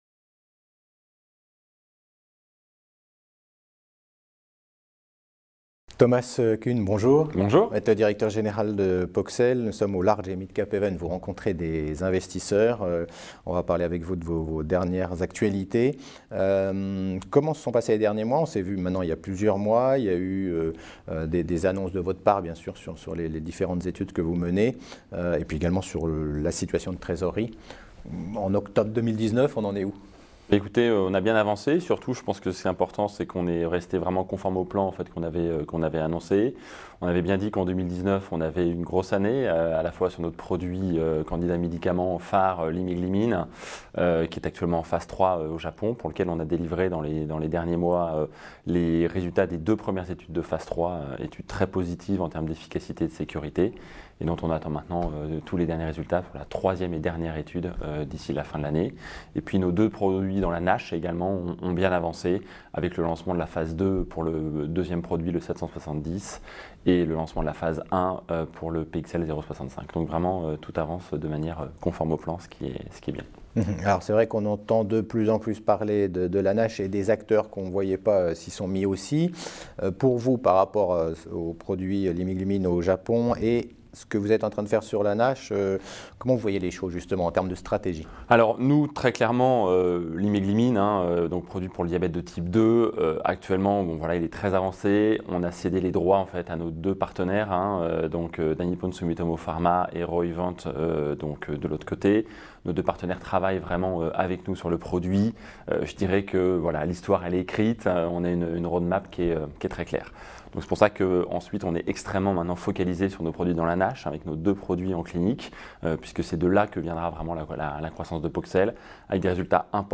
La Web Tv rencontre les dirigeants au Paris Large et Midcap Event 2019.